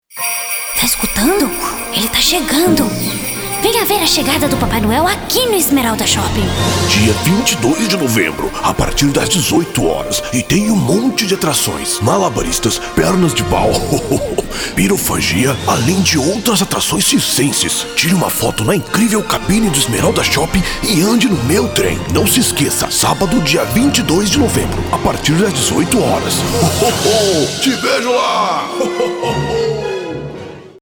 Masculino
ATS - Seguros (Voz Grave)